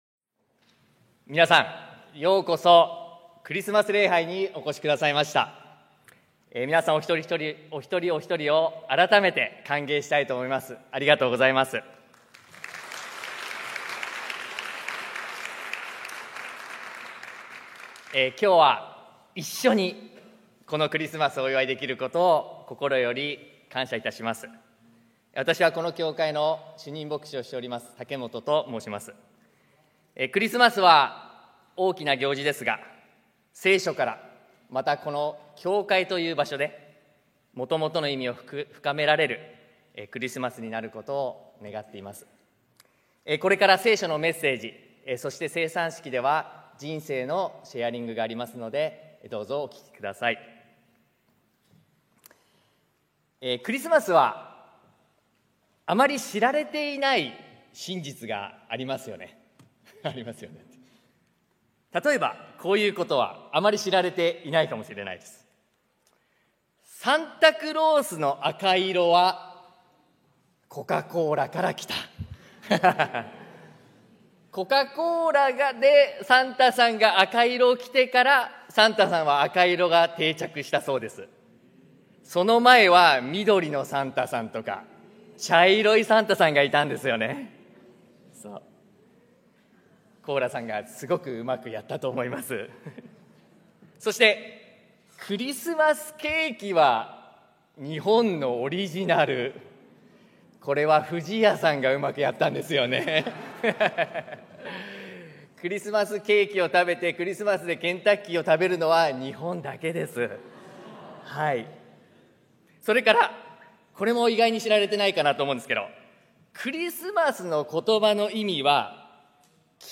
東京キリストの教会 日曜礼拝説教